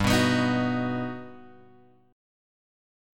G+M7 chord {3 2 x 4 4 2} chord